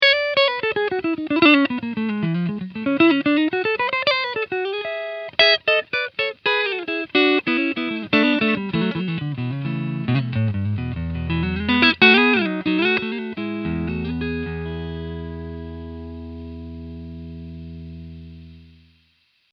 Clean riff 1